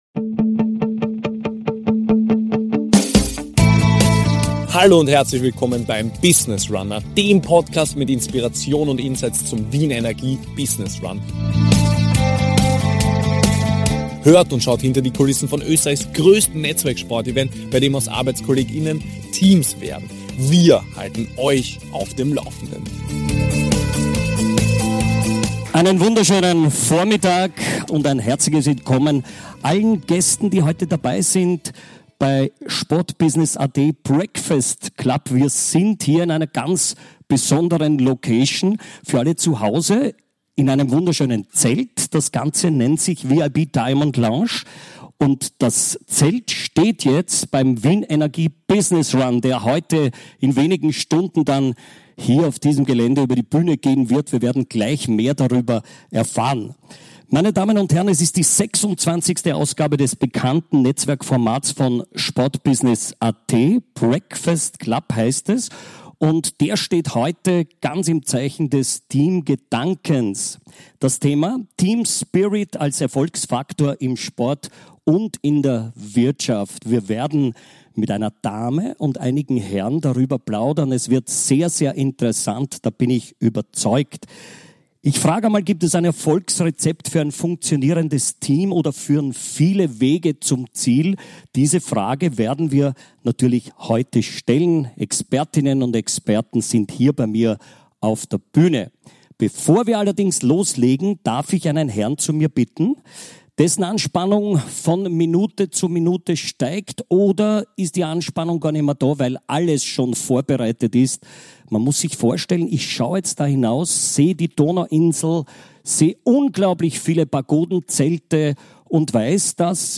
Live vor Publikum auf der Donauinsel